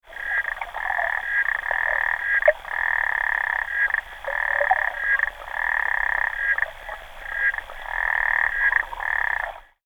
دانلود صدای قور قور قورباغه در بیشه از ساعد نیوز با لینک مستقیم و کیفیت بالا
جلوه های صوتی